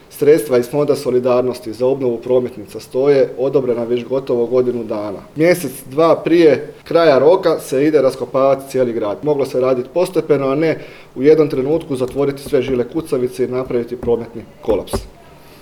na danas održanoj tiskovnoj konferenciji